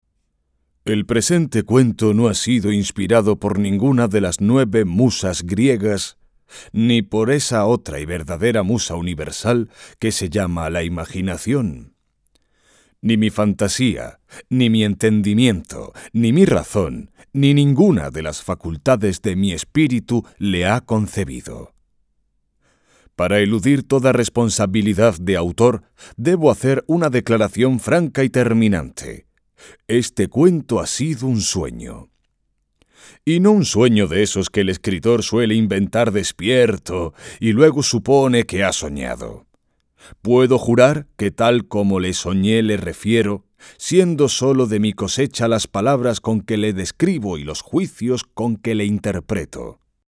Audiolibro La bruja del ideal de Antonio Alcalá Galiano